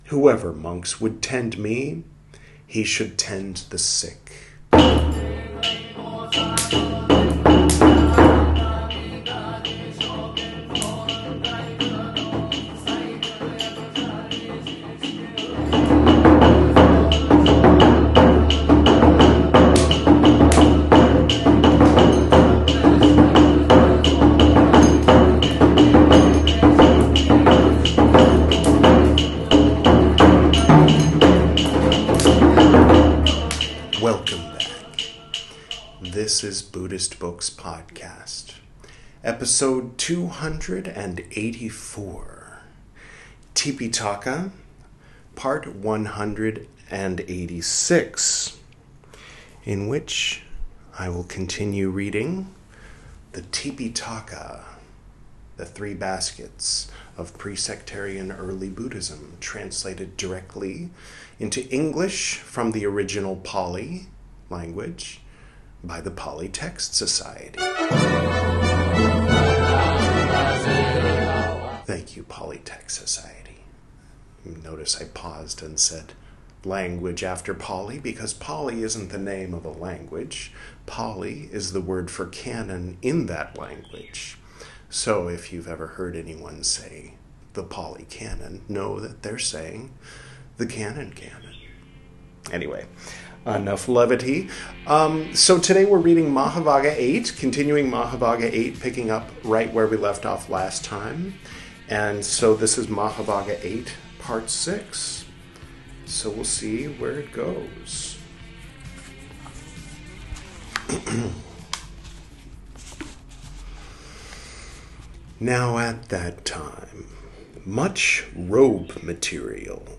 This is Part 186 of my recital of the 'Tipiṭaka,' the 'Three Baskets' of pre-sectarian Buddhism, as translated into English from the original Pali Language. In this episode, we'll continue reading 'Mahāvagga VIII,' from the 'Vinaya Piṭaka,' the first of the three 'Piṭaka,' or 'Baskets.'